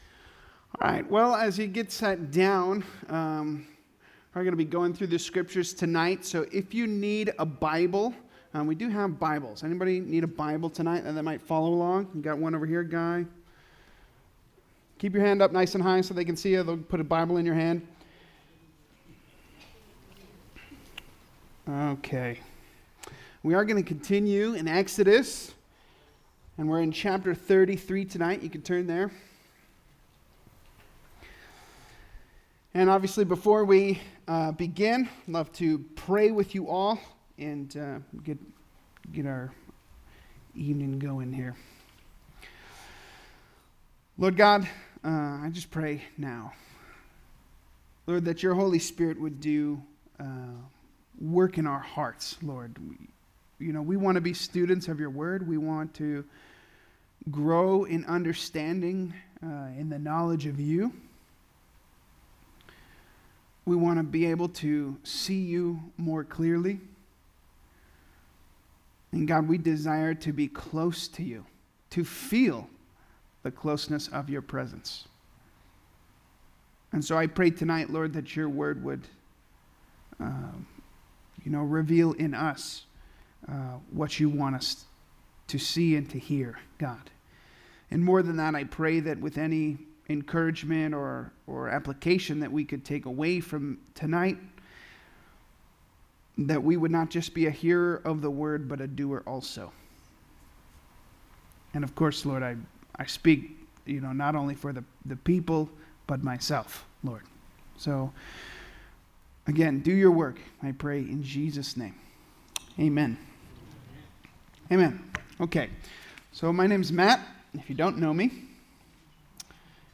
Calvary Chapel Saint George - Sermon Archive
Related Services: Wednesday Nights